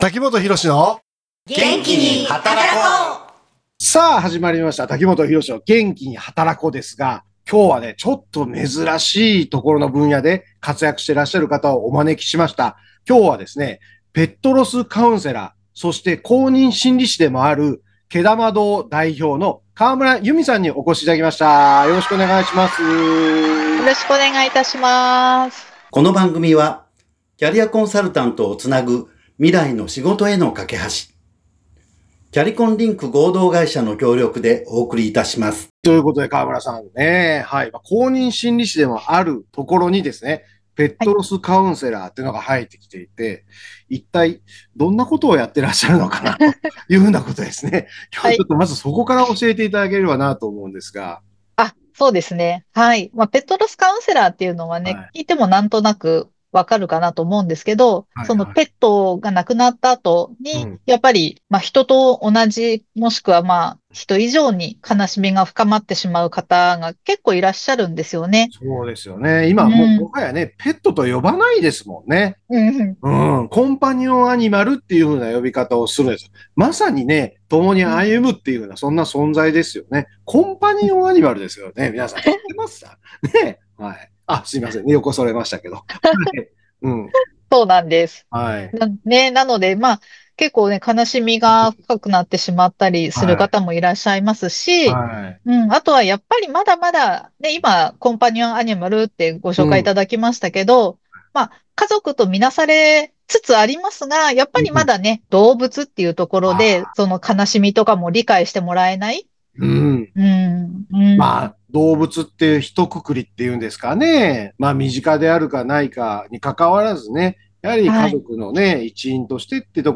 ペットとの生活をより豊かにし、心のケアを深めるラジオ放送です。